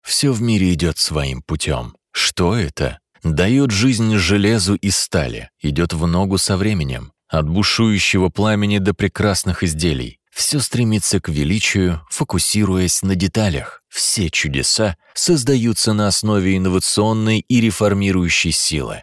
俄语样音试听下载
俄语配音员（男4）